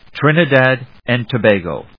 Trin・i・dad and To・ba・go /trínəd`ædən(d)təbéɪgoʊ‐gəʊ/